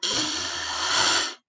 Звуки муравьеда
Недовольное шипение